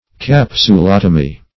Search Result for " capsulotomy" : The Collaborative International Dictionary of English v.0.48: Capsulotomy \Cap`su*lot"o*my\, n. [Capsule + Gr.